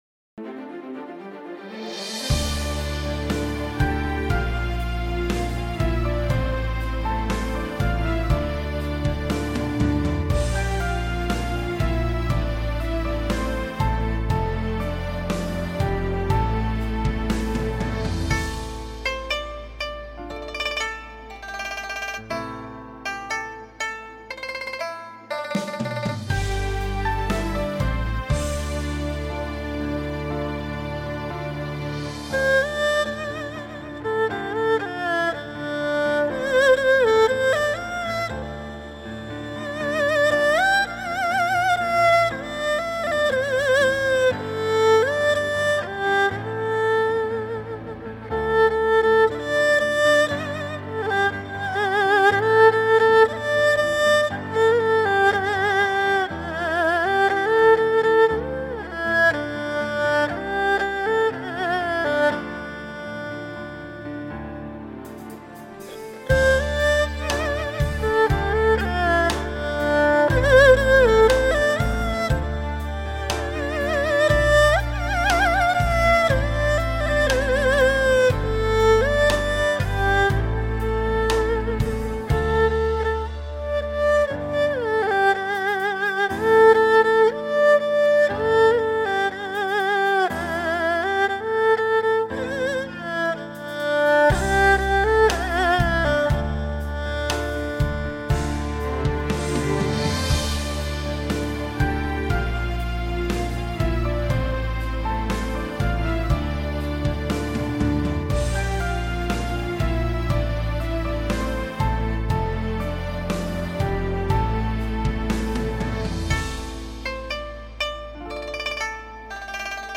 乐器：二胡